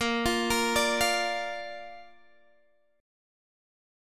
A# chord